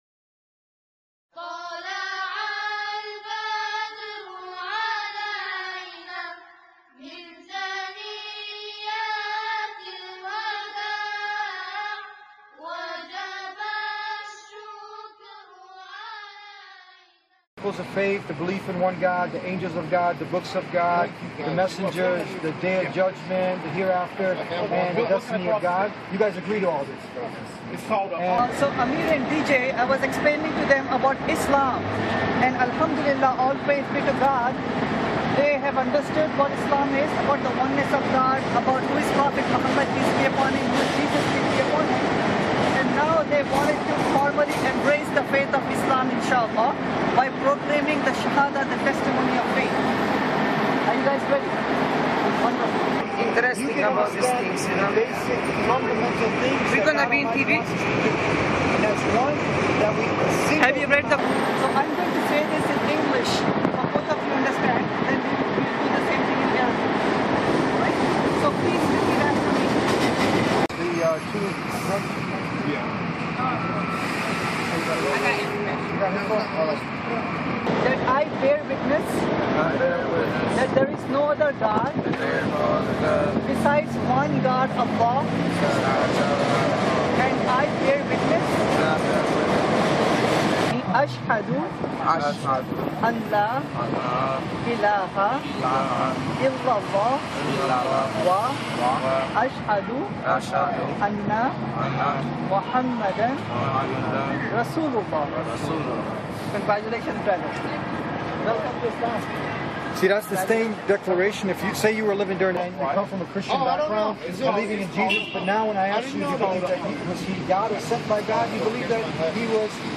Crying Shahada
Alhamdulillah, All praise be to Allah, the team of GainPeace and Deen Show, invite people to Islam in Chicago’s bus and train stations. On Sept 6th, 2012, they had 4 individuals embrace Islam, including the ‘Crying Shahada’.